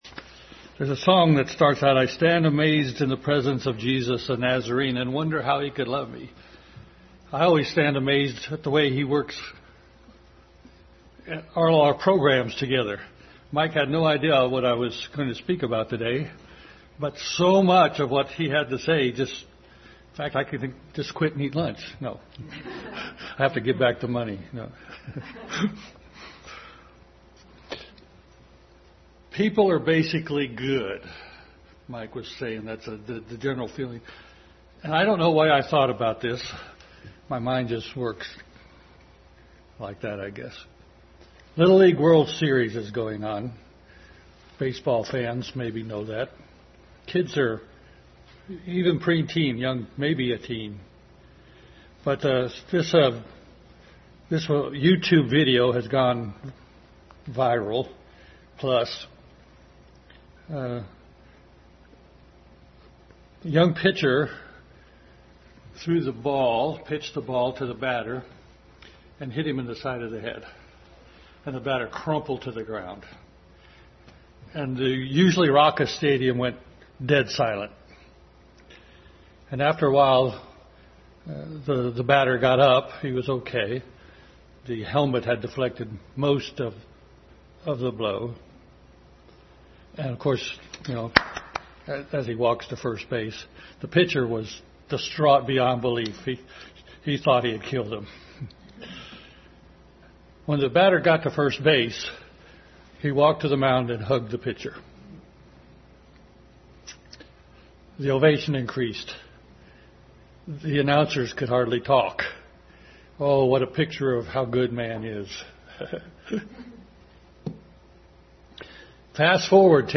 Family Bible Hour Message